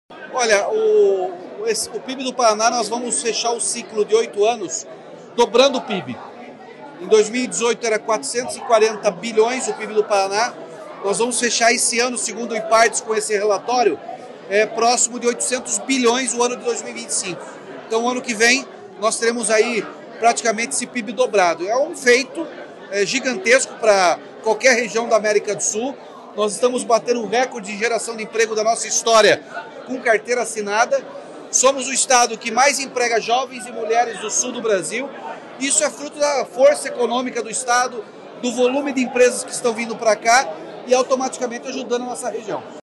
Sonora do governador Ratinho Junior sobre o crescimento do PIB no Paraná